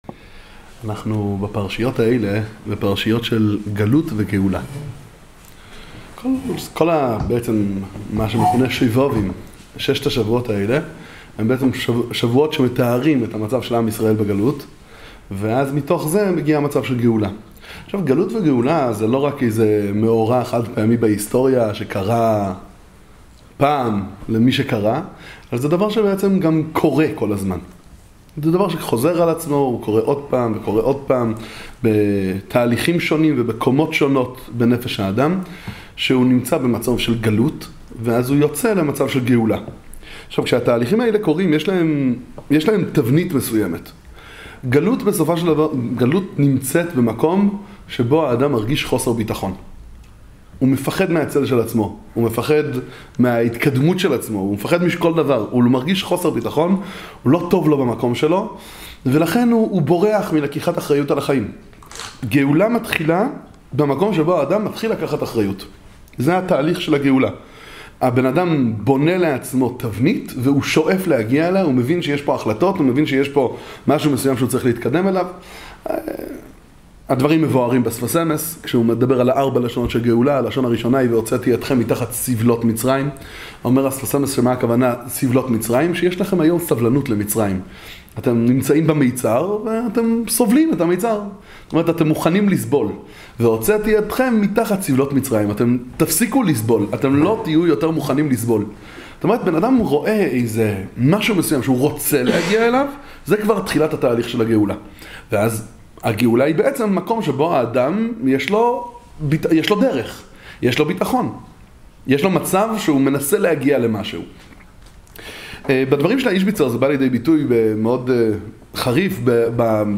שיעור בספר מי השילוח איז'ביצא